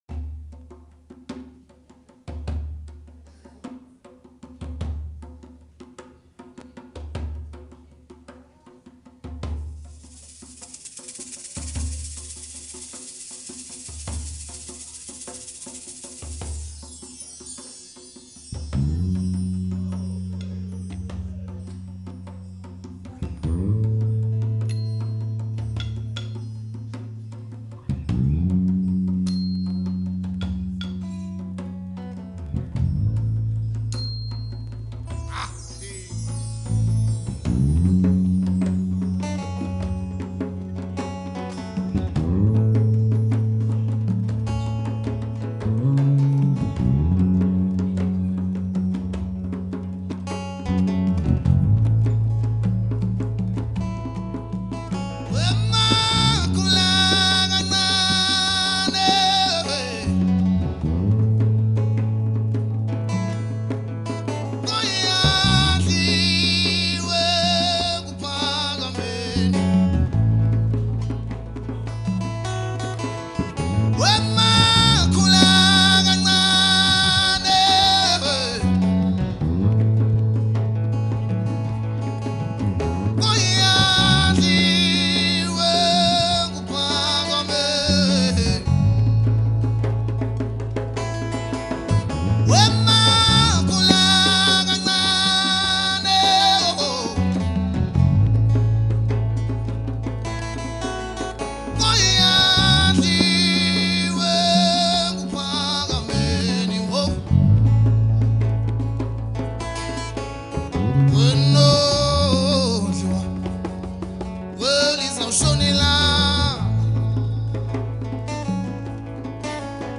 Zulu Maskandi